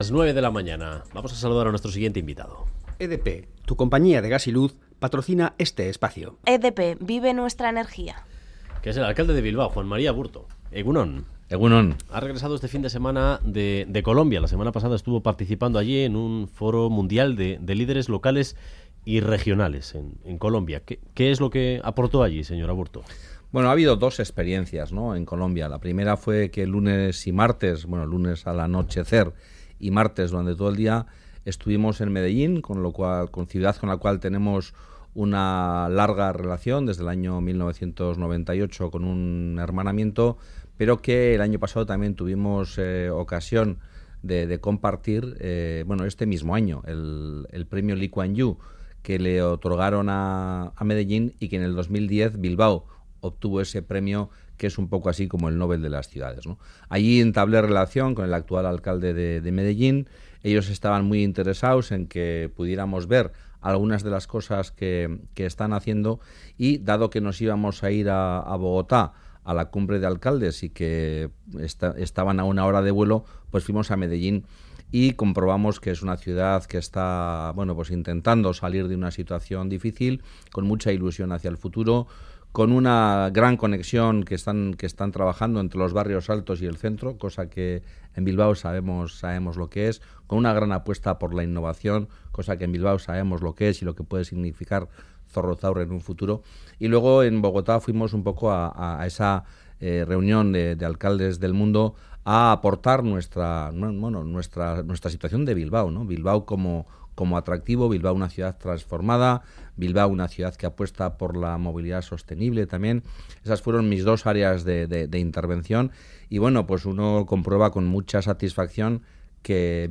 Audio: El alcalde de Bilbao señala en Radio Euskadi que hay que dar mas pasos hacia la paz y que ésta exige exige generosidad por ambas partes